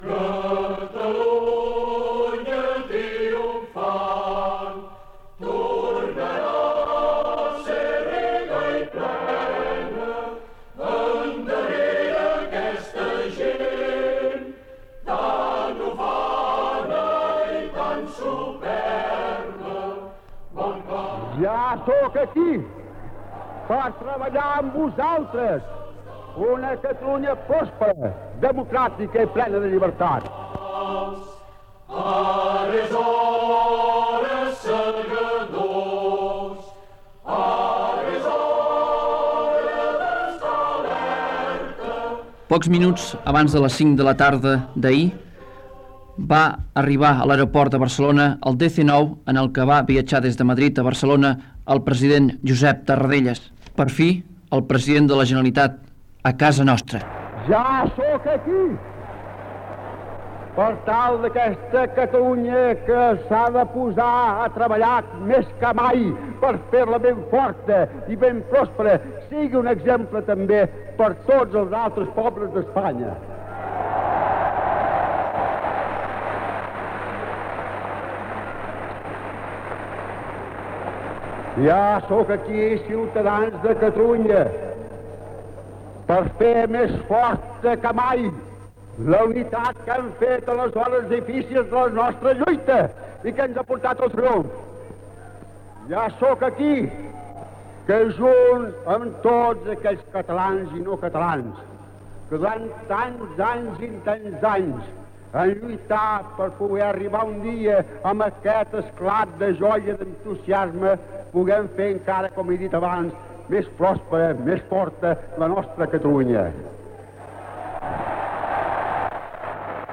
6553af5c41058af1282f1f7218ba372d3e813573.mp3 Títol Ràdio Sabadell EAJ-20 Emissora Ràdio Sabadell EAJ-20 Titularitat Privada local Descripció Reportatge sobre el retorn a Catalunya del president de la Generalitat Josep Tarradellas el dia anterior amb fragments dels seus parlaments. Resum de premsa.
Paraules del president del govern Adolfo Suárez